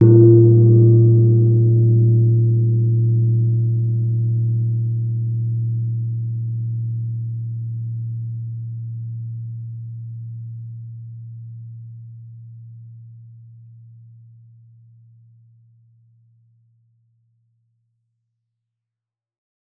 jing_cotton_ord-A0-mf.wav